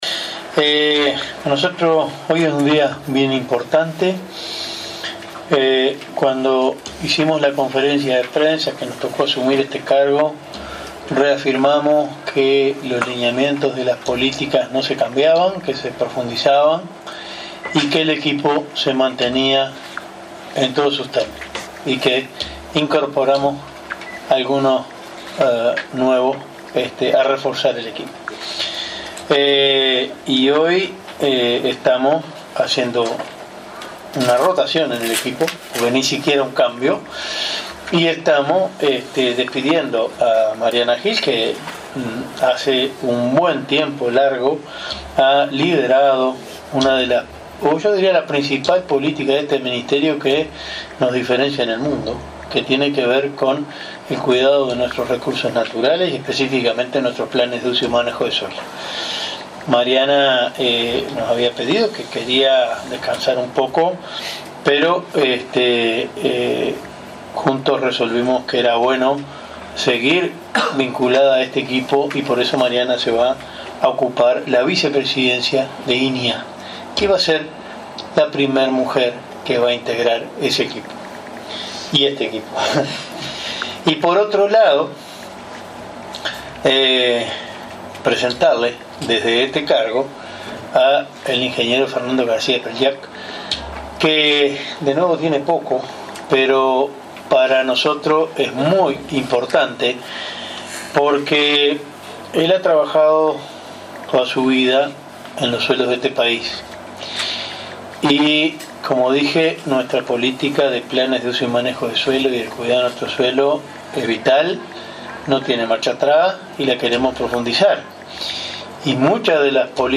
“La conservación de suelos y cuidado de recursos naturales es la principal política que nos diferencia internacionalmente, no tiene marcha atrás y la queremos profundizar”, sostuvo el ministro de Ganadería, Enzo Benech, en la asunción de Fernando García Préchac como director general de Recursos Naturales, en sustitución de Mariana Hill, quien asumirá la vicepresidencia del Instituto Nacional de Investigación Agropecuaria.